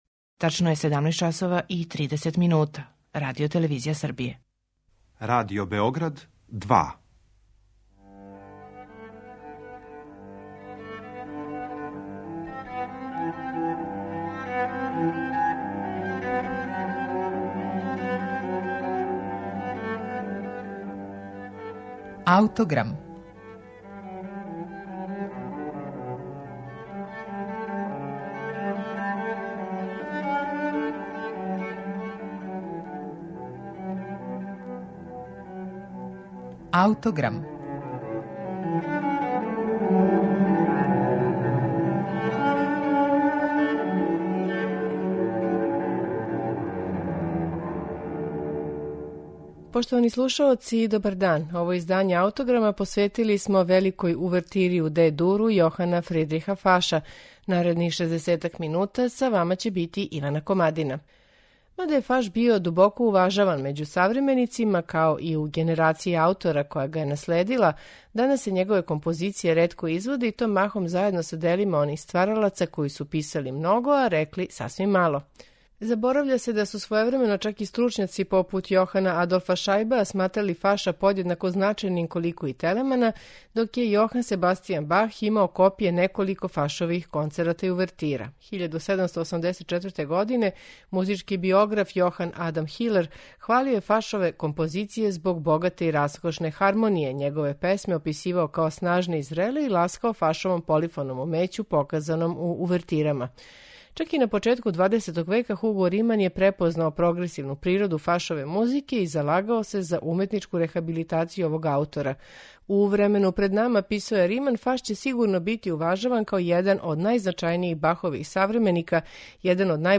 Задивљујуће је контрапунктско мајсторсто у брзој фуги уводног става, који уједно илуструје Фашову фасцинираност колоритом оркестарког звука.